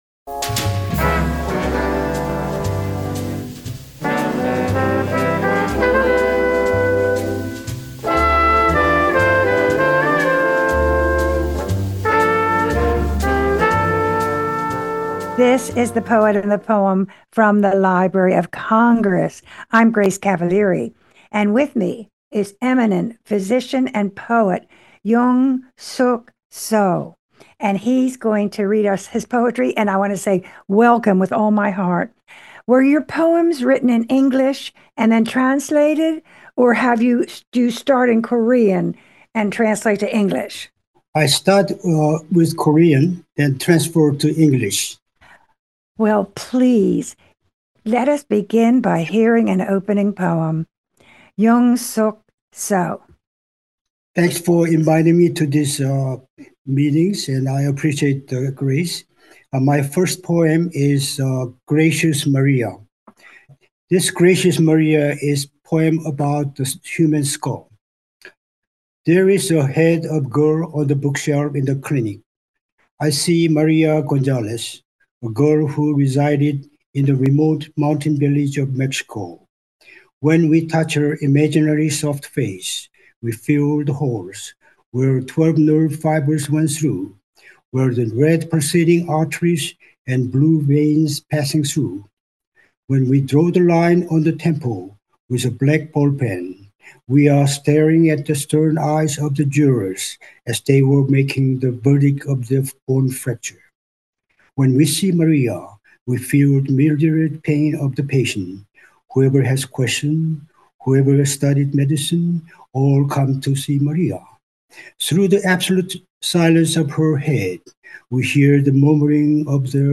Interviews with U.S. Poets Laureate